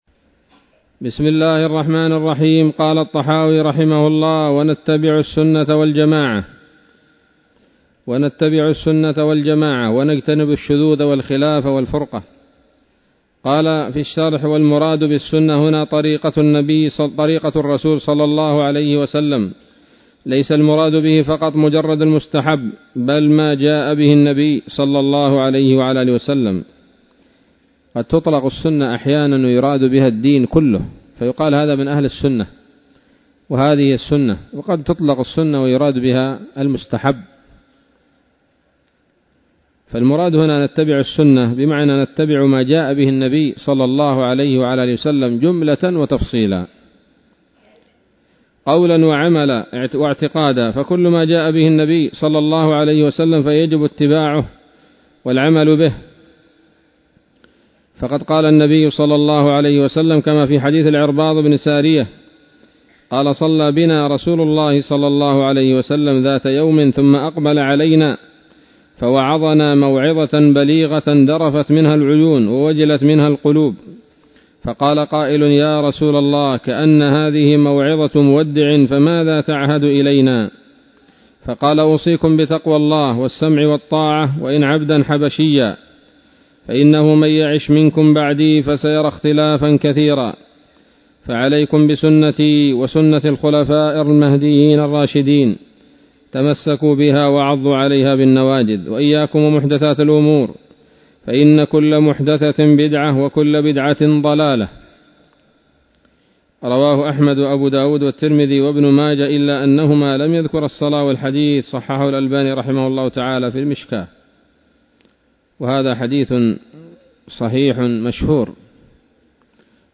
الدرس السابع والتسعون